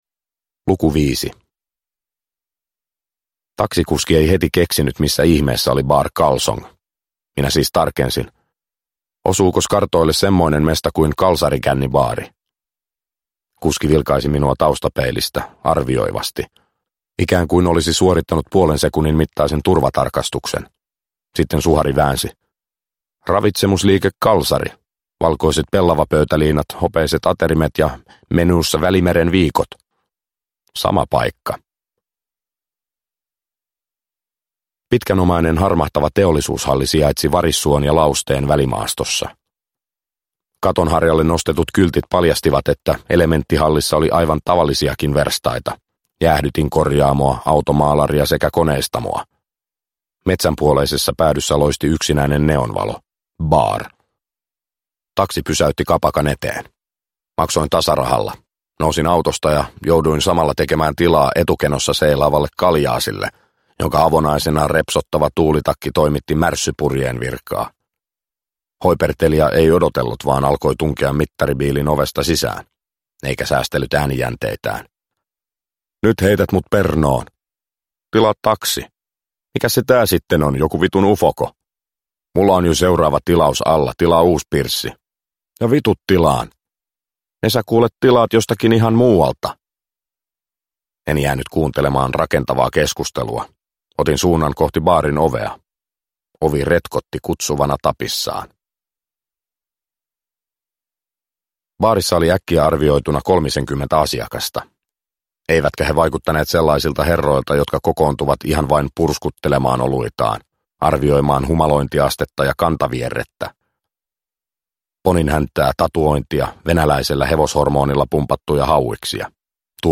Intiaani – Ljudbok – Laddas ner